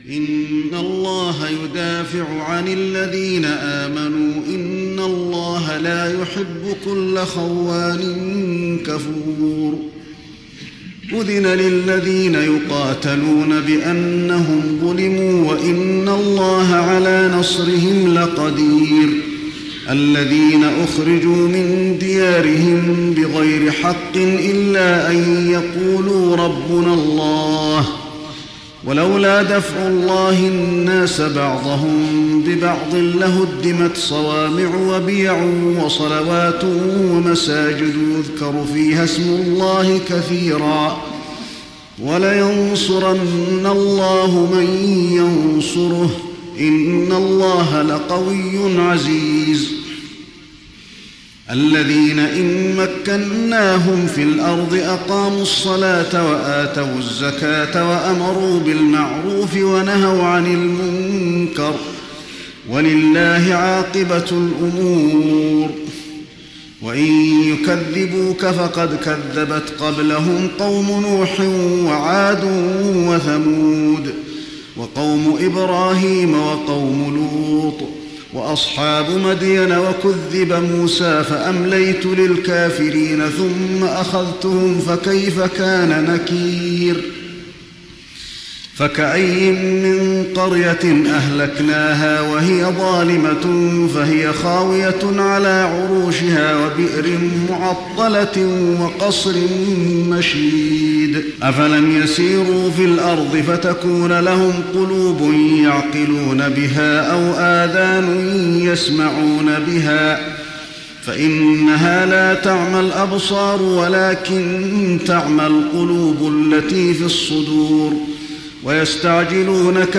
تراويح رمضان 1415هـ من سورتي الحج (38-78) و المؤمنون كاملة Taraweeh Ramadan 1415H from Surah Al-Hajj and Al-Muminoon > تراويح الحرم النبوي عام 1415 🕌 > التراويح - تلاوات الحرمين